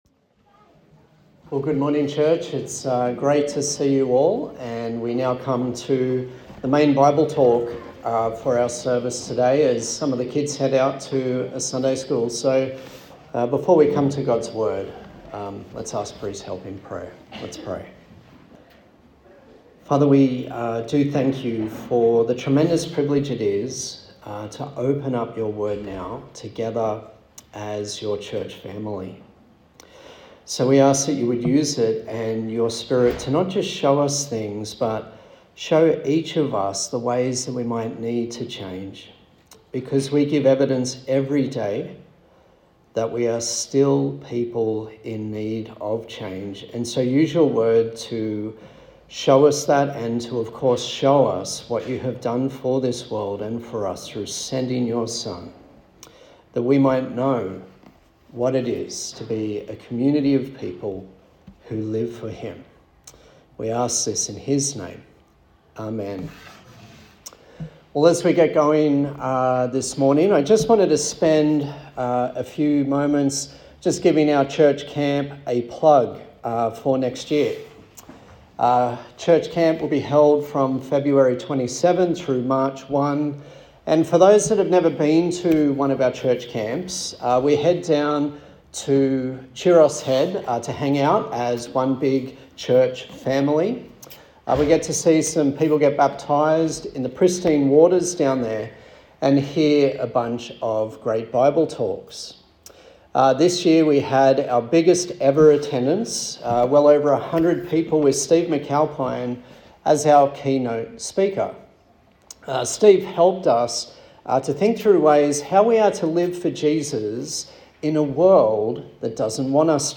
A sermon in the series Kingdom Come from the Gospel of Matthew
Service Type: Sunday Morning